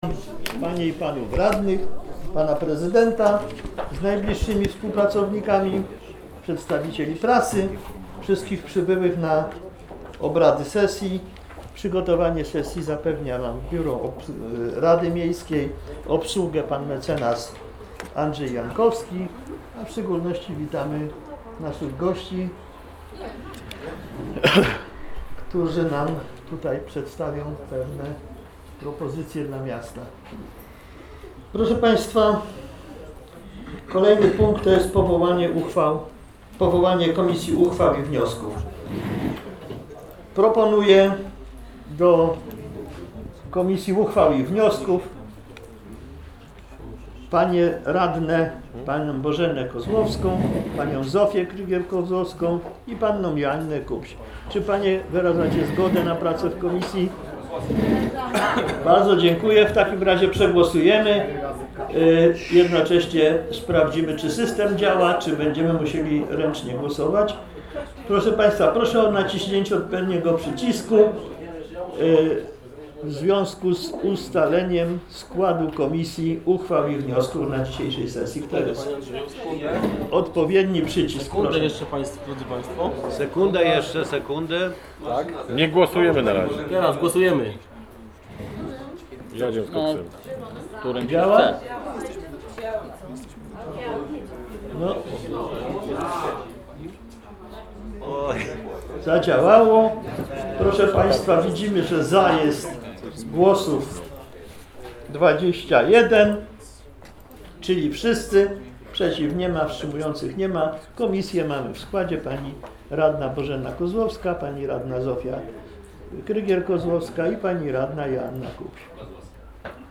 LX sesja Rady Miejskiej w Pabianicach - 11 października 2018 r. - 2018 rok - Biuletyn Informacji Publicznej Urzędu Miejskiego w Pabianicach